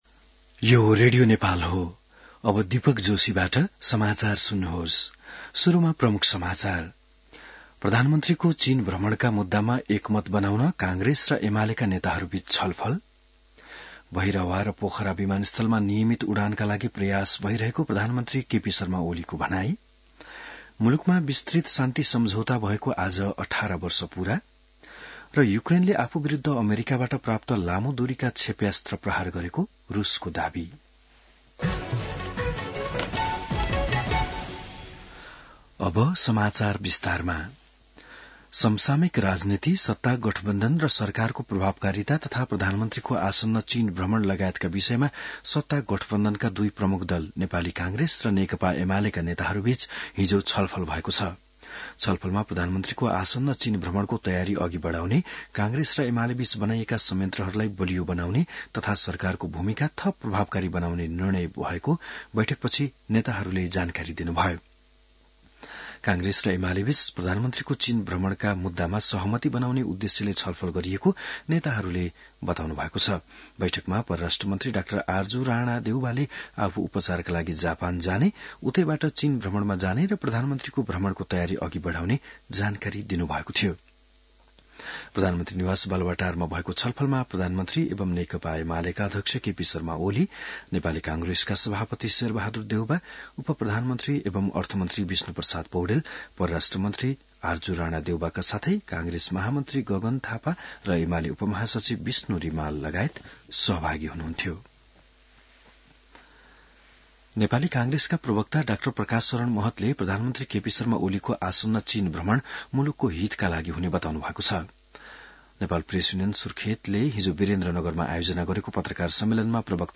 An online outlet of Nepal's national radio broadcaster
बिहान ९ बजेको नेपाली समाचार : ६ मंसिर , २०८१